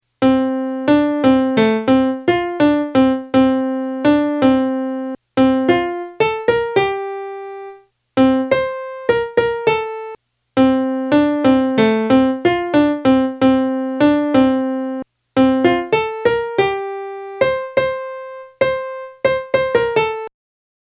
la-smortina-melody.mp3